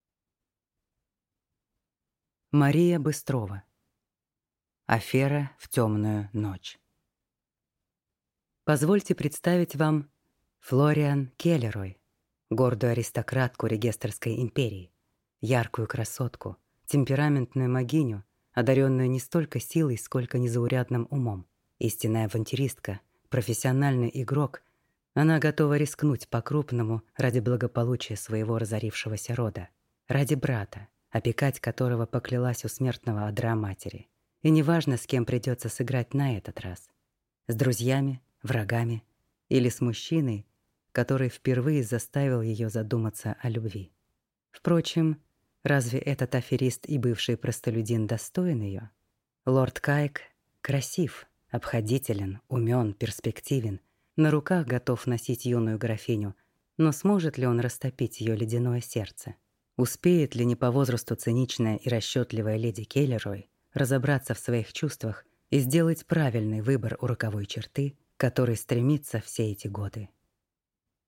Аудиокнига Афера в Темную ночь | Библиотека аудиокниг